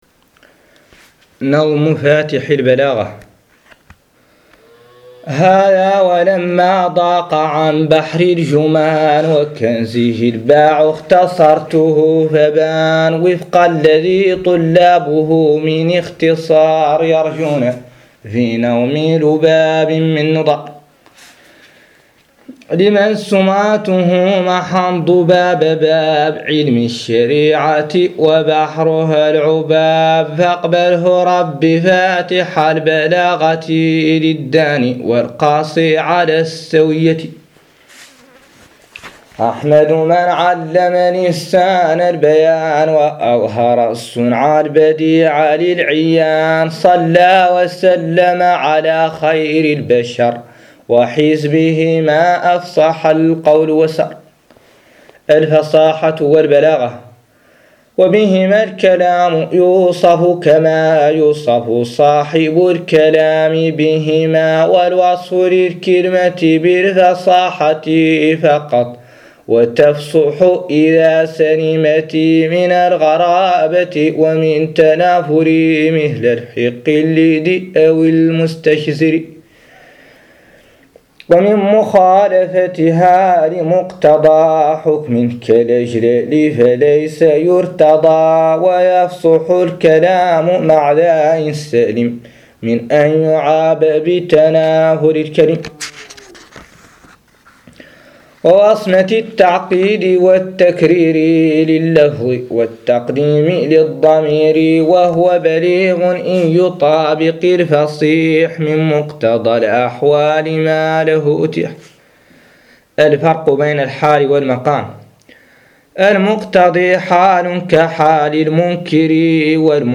قراءة نظم فاتح البلاغة - الجزء الاول